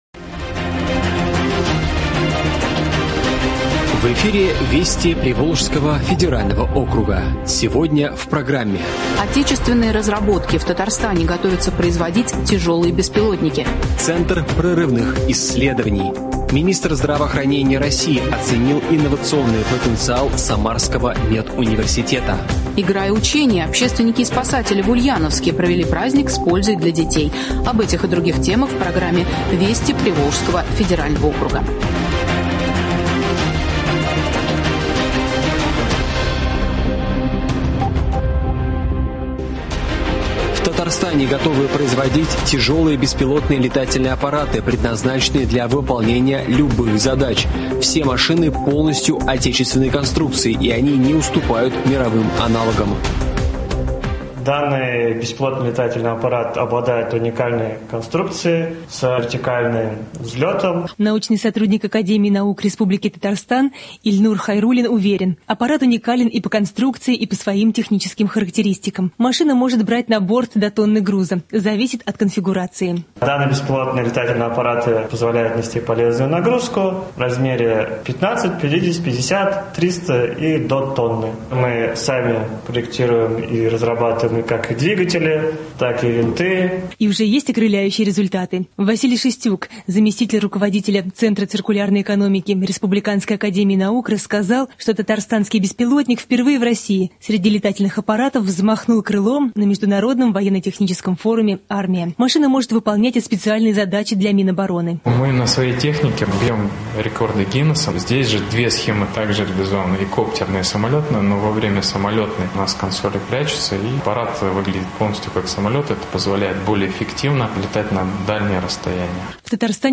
Радиообзор событий недели в регионах ПФО .